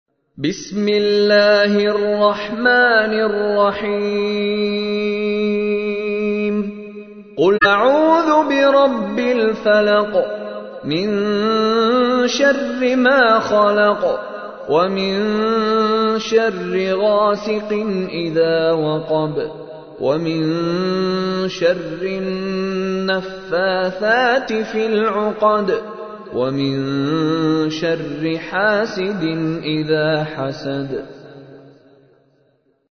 تحميل : 113. سورة الفلق / القارئ مشاري راشد العفاسي / القرآن الكريم / موقع يا حسين